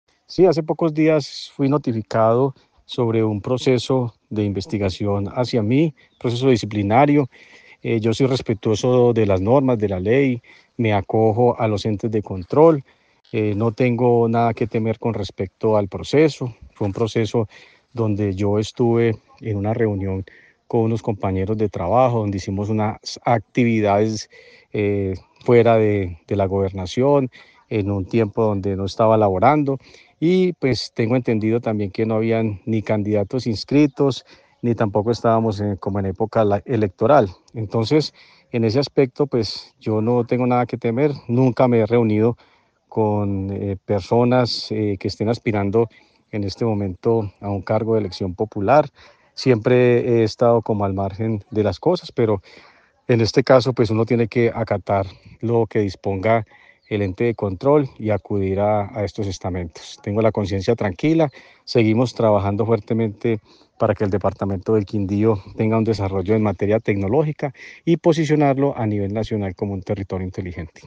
Secretario de las TIC
En diálogo con Caracol Radio el funcionario dio a conocer que hace unos días fue notificado de un proceso de investigación en su contra.